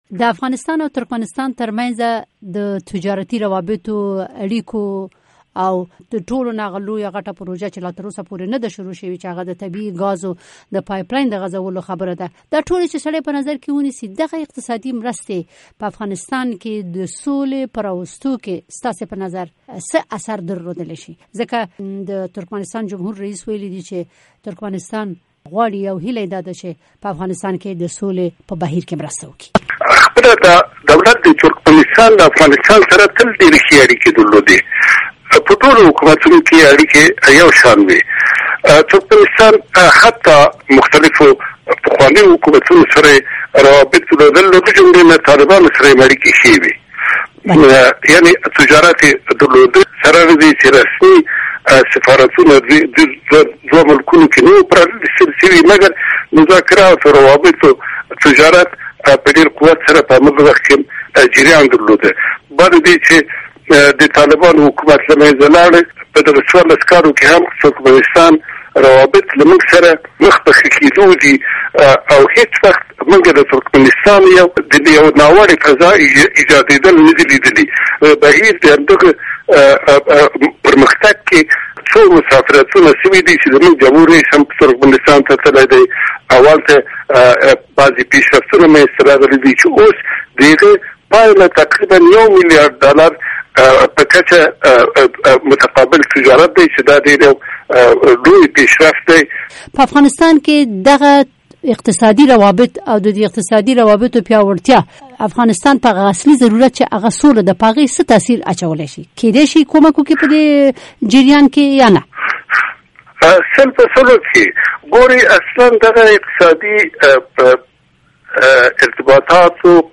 د ډاکټر کبیر رنجبر سره مرکه